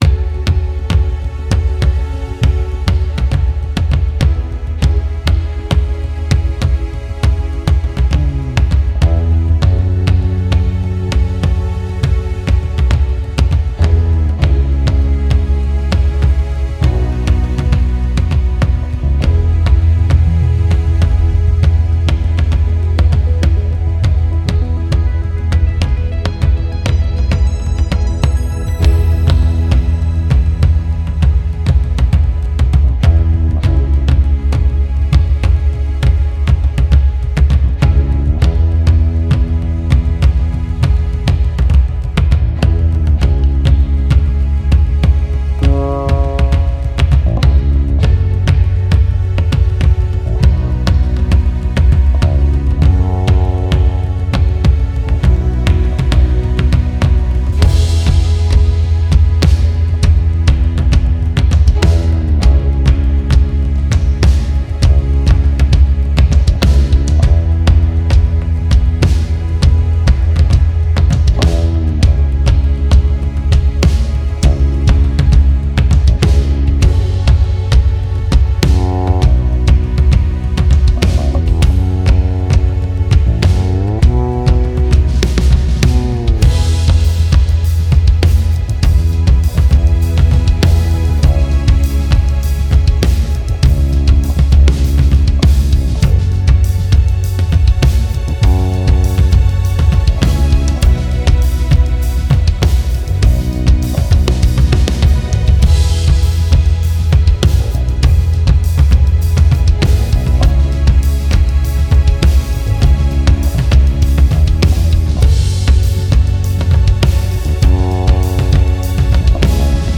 with Background Vocals w/o Background Vocals
Performance Track